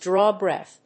アクセントdráw bréath